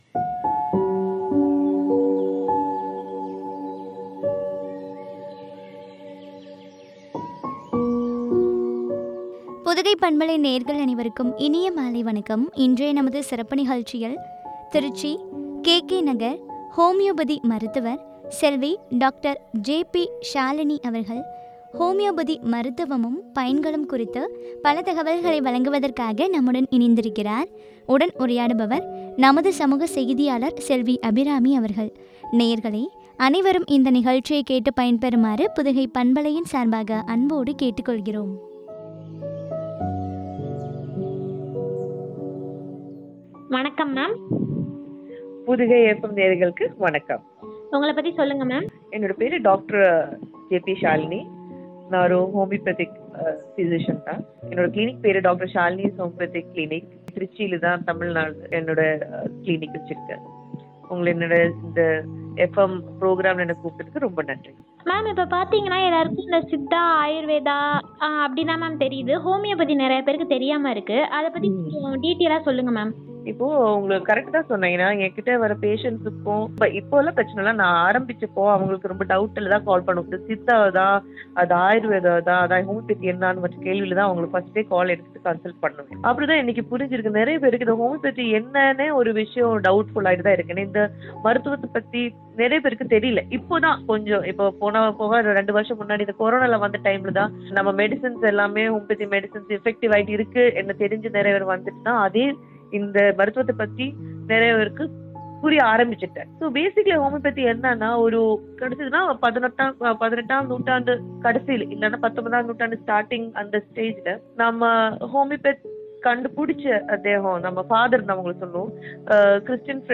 பயன்களும் பற்றிய உரையாடல்.